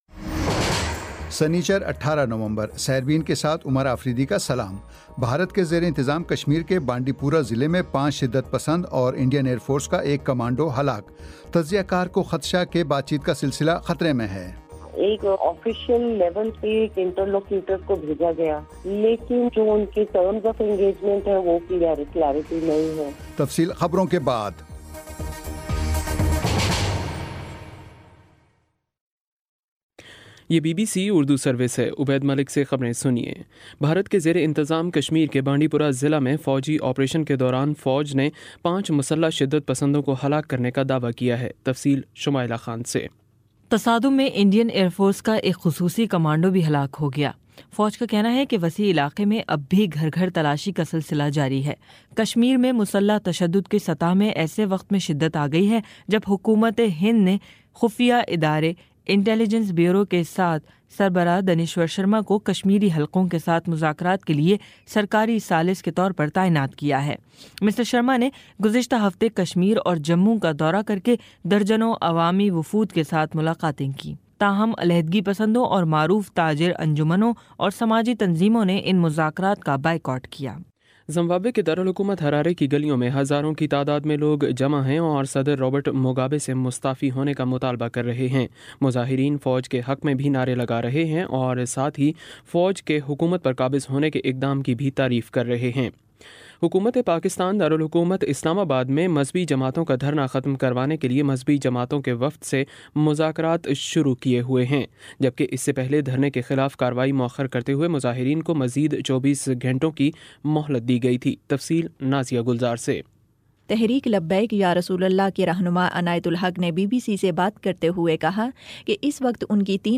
سنیچر 18 نومبر کا سیربین ریڈیو پروگرام